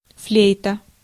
Ääntäminen
Vaihtoehtoiset kirjoitusmuodot (vanhentunut) floyte Ääntäminen : IPA : /fluːt/ US : IPA : [fluːt] Lyhenteet ja supistumat (musiikki) Fl.